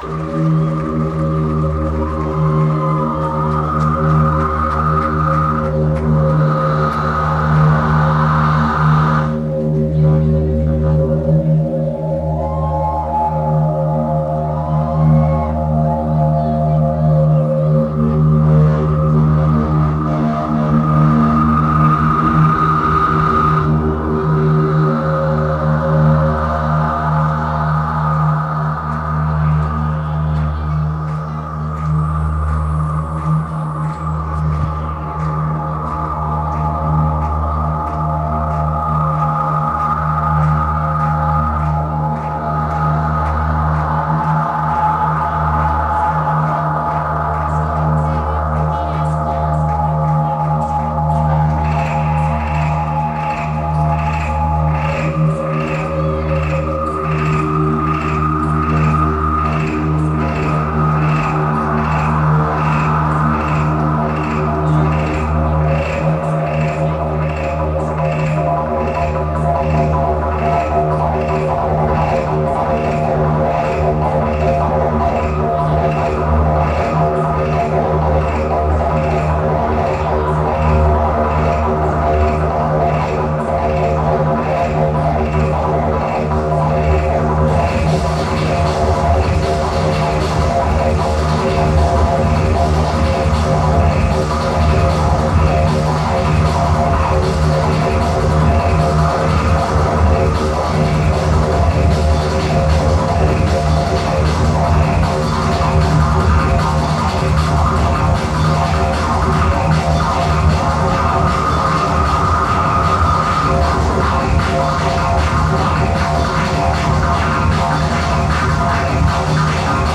venue Avalon Ballroom